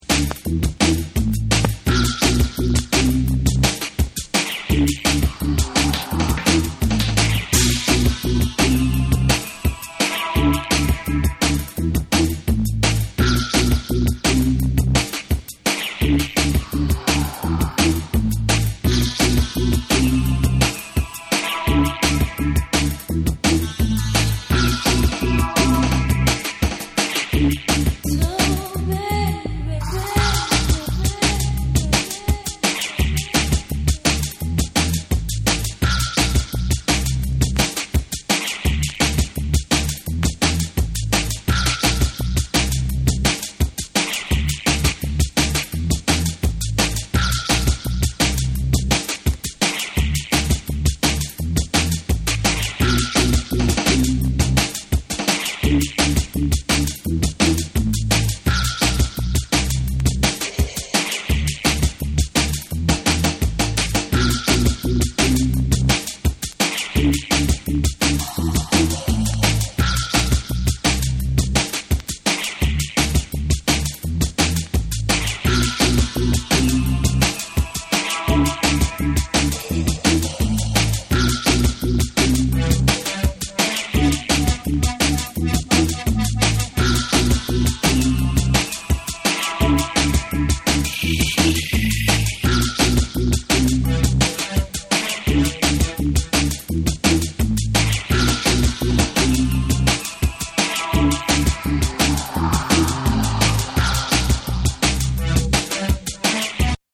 タイトなバウンシー・リズムに幻想的に奏でるシンセが絡む2。